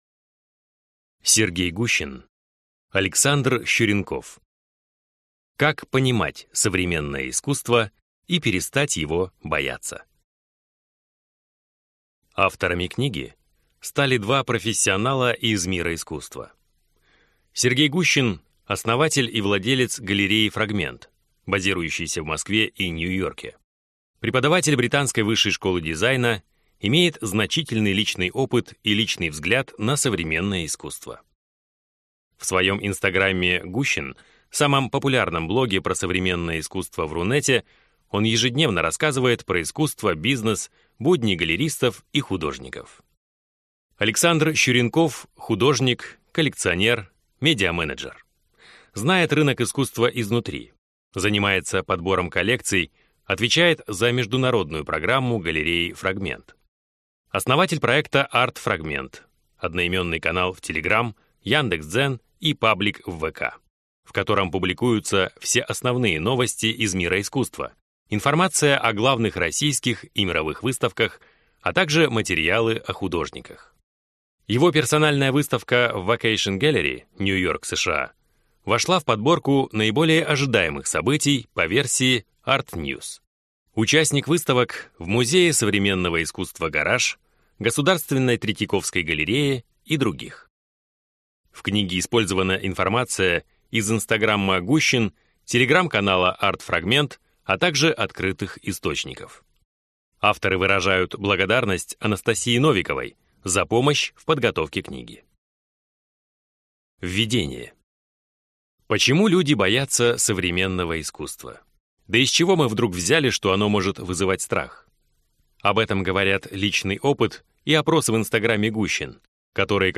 Аудиокнига Современное искусство и как перестать его бояться | Библиотека аудиокниг